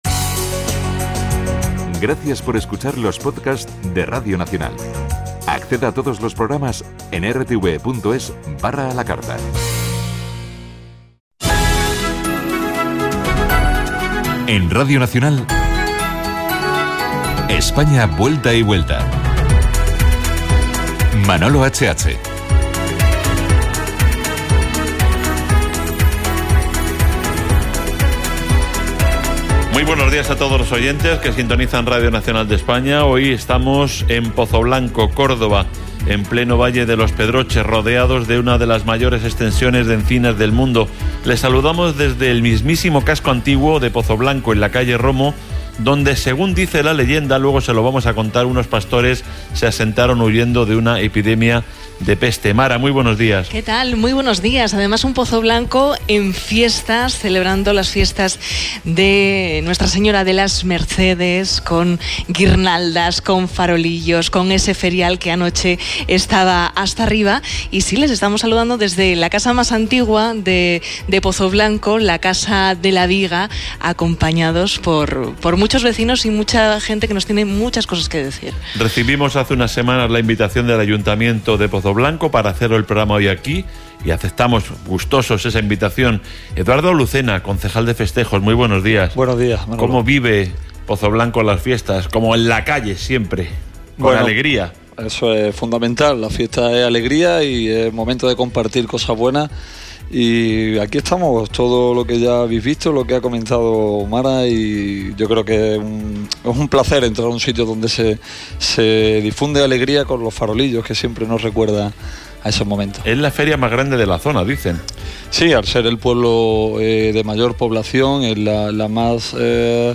El programa "España vuelta y vuelta", de Radio Nacional de España y presentado por Manolo HH y Mara Peterssen, desde la Casa de la Viga de Pozoblanco.
Hubo entrevistas, música, etc. que ahora podemos escuchar aquí: